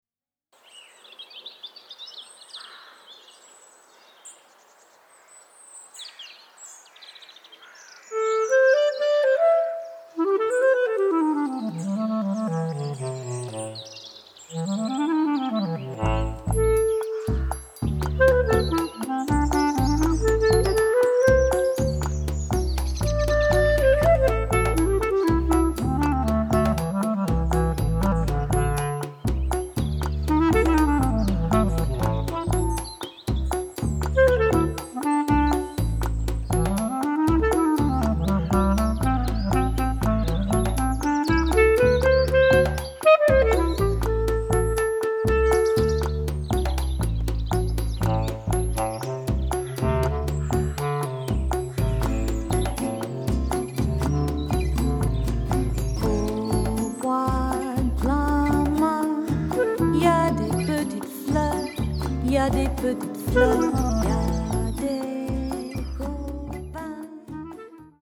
clarinettist/saxophonist